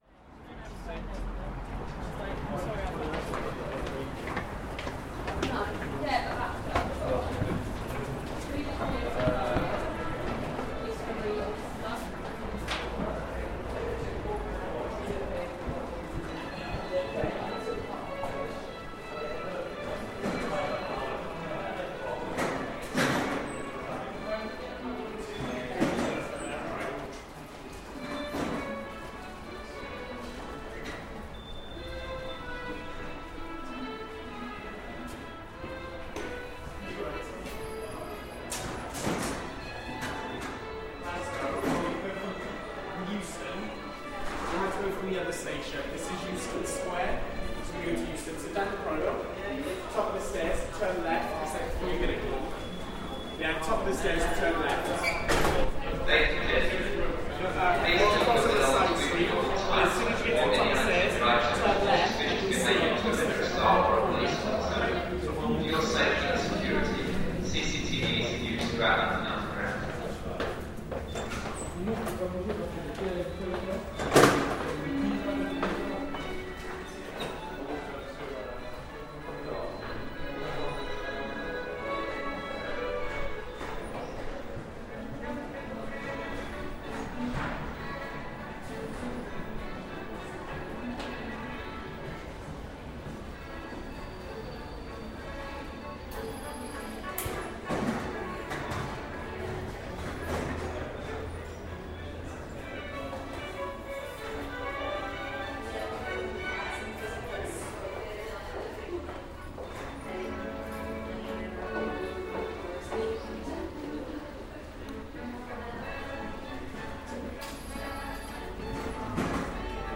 Piped classical music at Euston Square
Field recording from the London Underground by Cities and Memory.